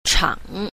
b. 場 – chǎng – trường